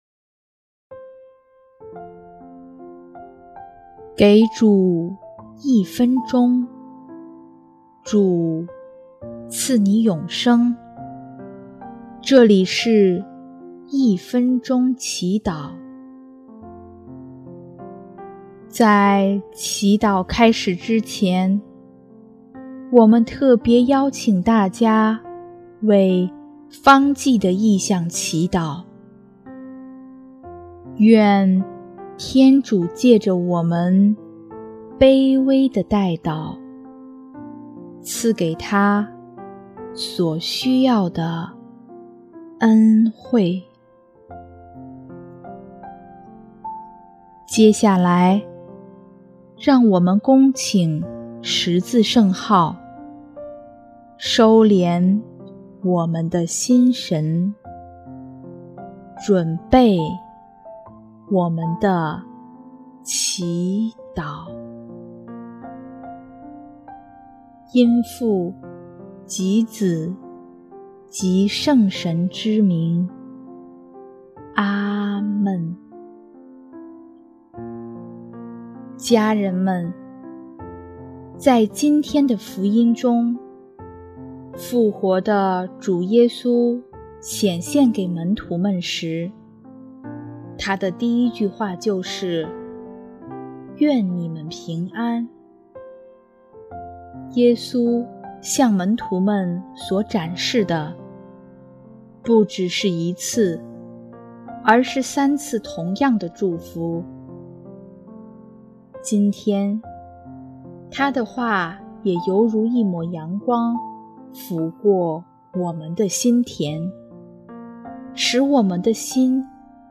第二届华语圣歌大赛参赛歌曲《耶稣的微笑》